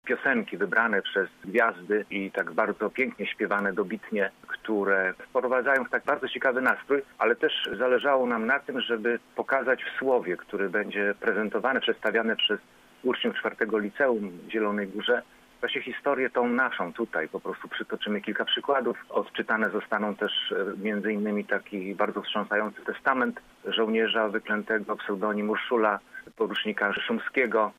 Mówi Marek Budniak, historyk i radny klubu Prawa i Sprawiedliwości oraz pełnomocnik wojewody do spraw kombatantów i osób represjonowanych: